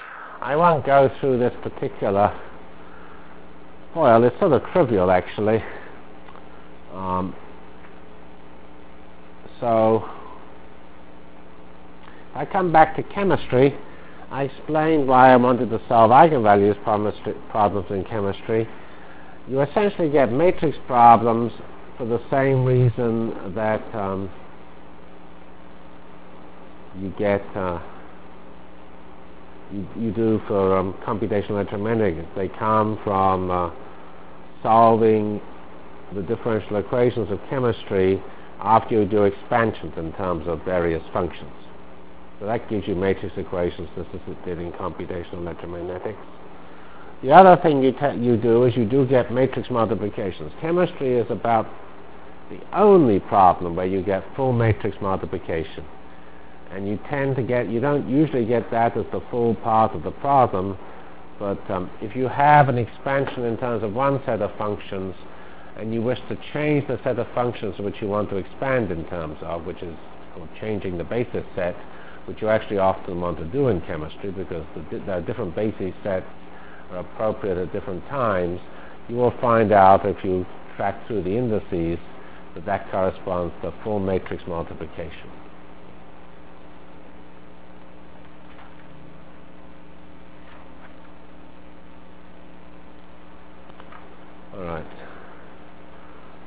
Delivered Lectures of CPS615 Basic Simulation Track for Computational Science